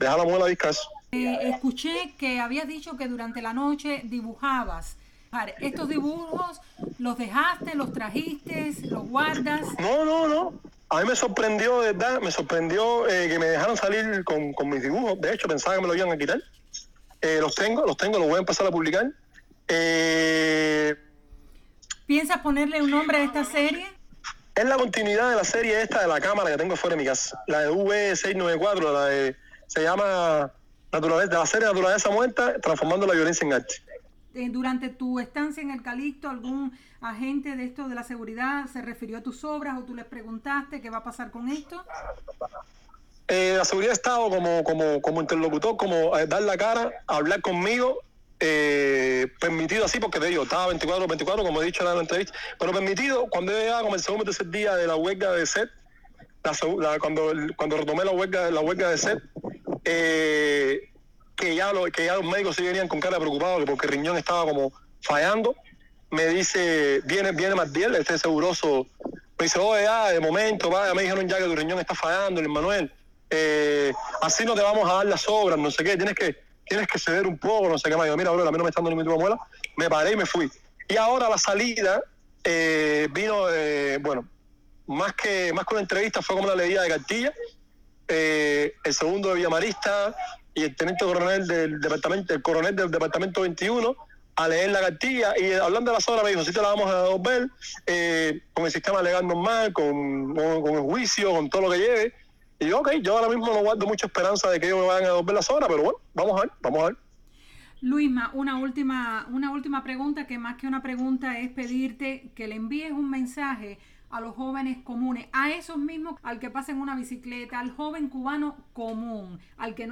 Otero Alcántara responde a las preguntas de Radio Martí (2)